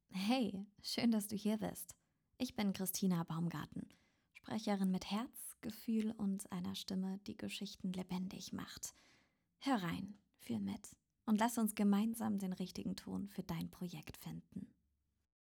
Emotionale Stimme auf Deutsch & Englisch.
• weiblich
• emotional | dynamisch | sanft |
• Junge, frische Klangfarbe, die natürlich und sympathisch klingt